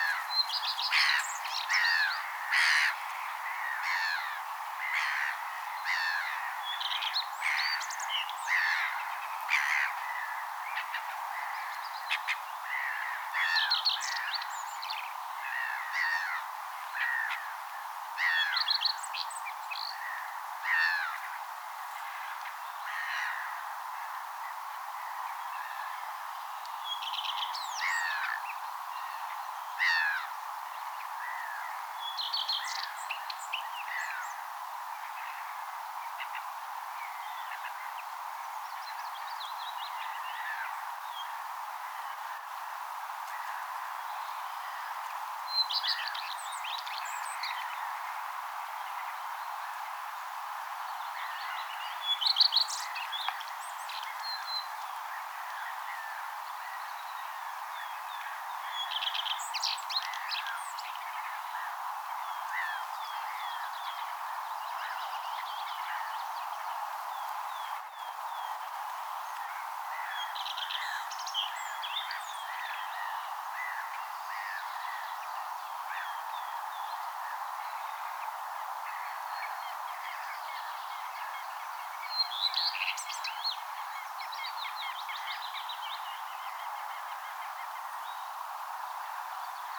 kevään ensimmäinen pajulintu,
kaksi leppälintua laulaa
kevaan_ensimmainen_pajulintu_laulaa_taustalla_leppalintu_laulaa_taustalla_toinenkin_leppalintu.mp3